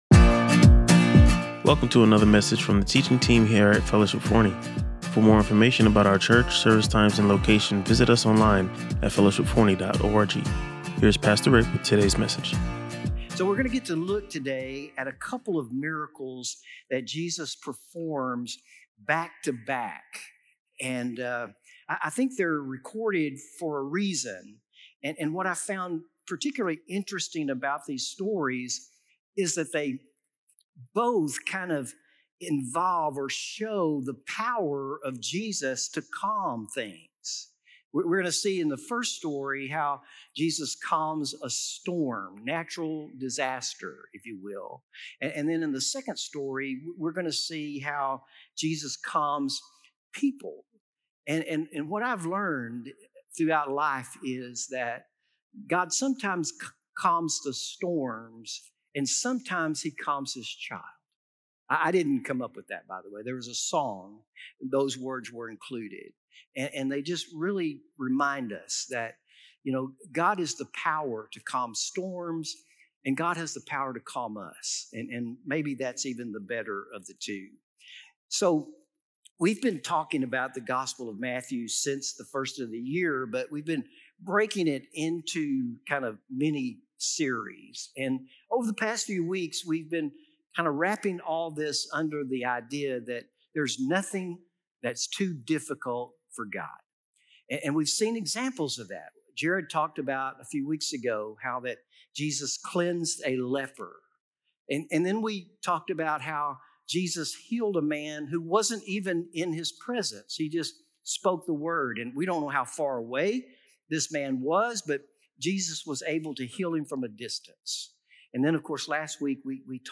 The sermon concluded with a compelling reminder that following Christ may come at a cost, but His peace surpasses all understanding. Listen to or watch the full sermon and discover how these